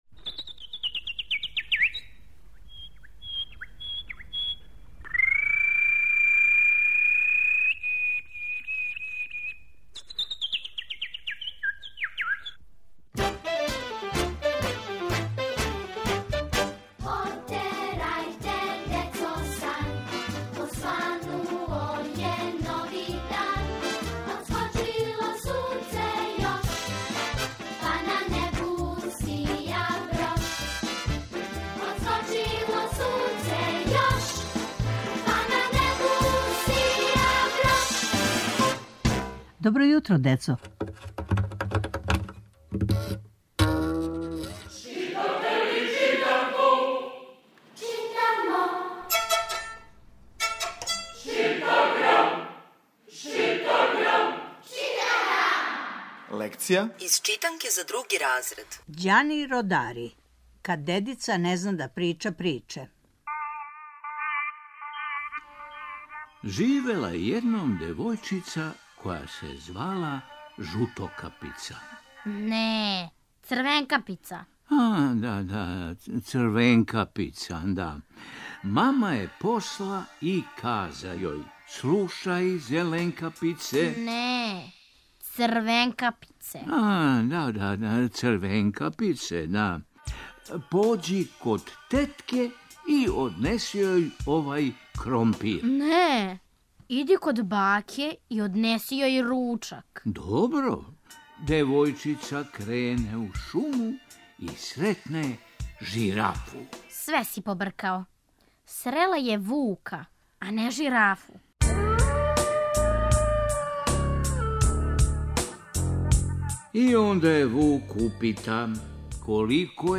Сваког понедељка у емисији Добро јутро, децо - ЧИТАГРАМ: Читанка за слушање. Ове недеље - други разред, лекција: "Кад дедица не зна да прича приче", кратка прича Ђанија Родарија.